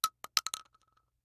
57e0746fe6 Divergent / mods / Bullet Shell Sounds / gamedata / sounds / bullet_shells / shotgun_generic_8.ogg 21 KiB (Stored with Git LFS) Raw History
shotgun_generic_8.ogg